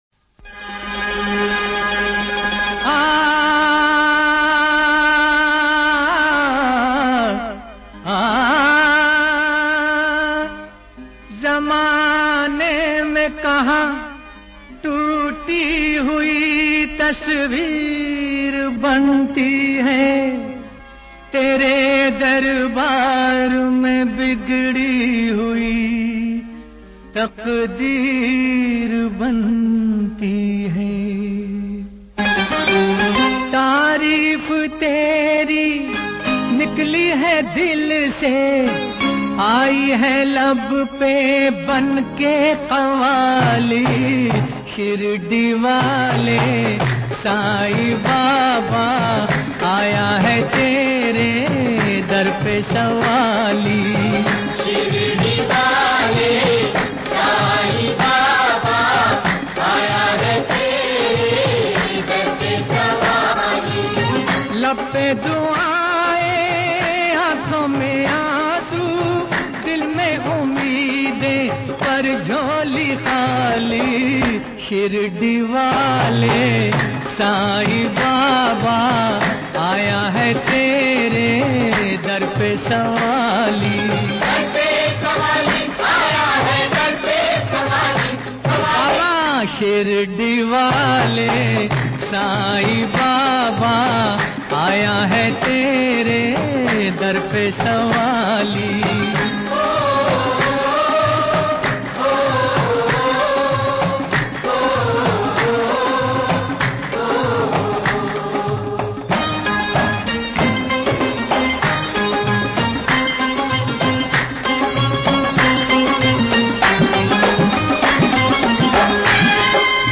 bhajan and qawali